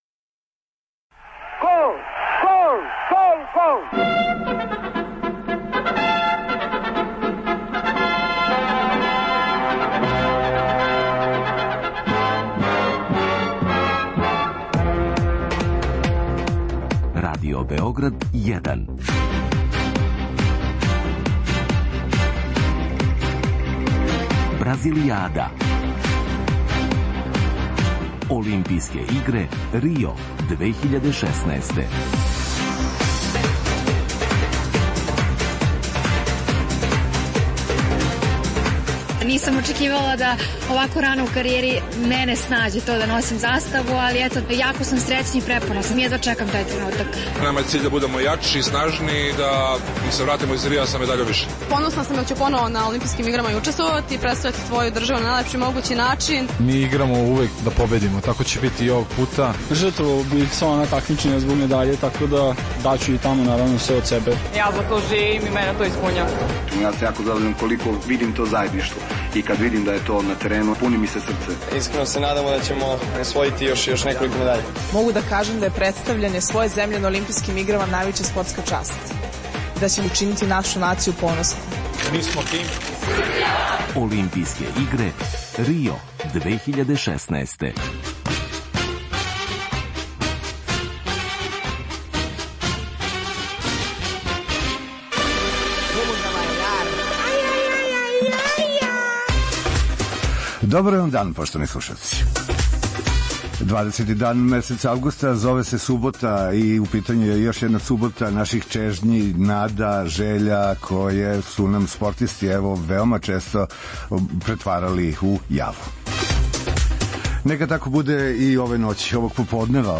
Поред преноса утамице кошаркашица Србије и Француске за 3.место на олимпијском турниру, и данас ћемо чути много прилога, изјава, анализа, коментара бивших спортских асова. Остатак емисије, као и обично суботом, посвећен је дешавањима у свету јавног, културног и уметничког живота Србије.